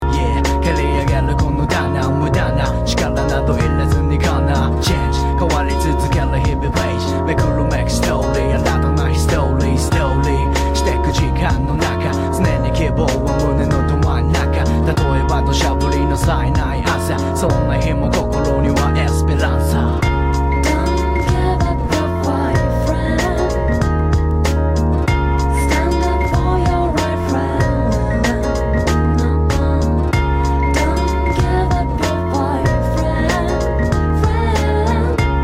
Tag       Japan R&B